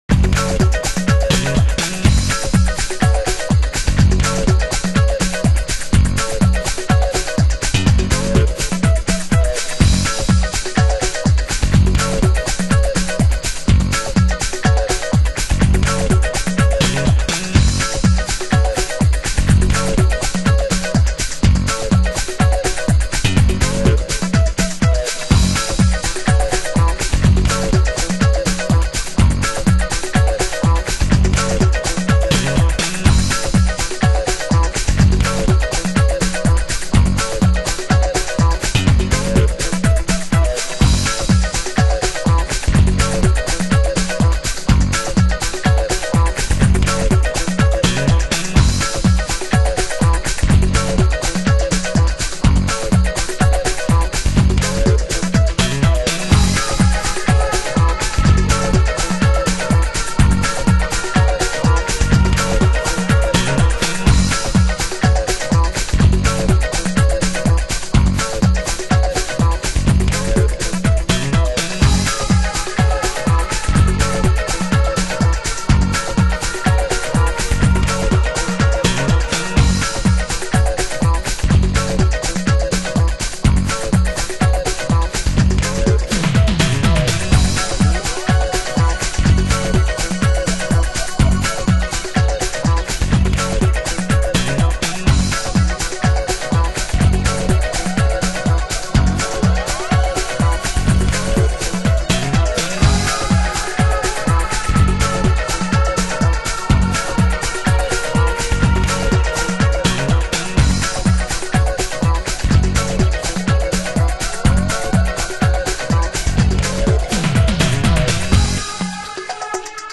HOUSE MUSIC
Extended Mix (針とびの箇所以降を録音しております)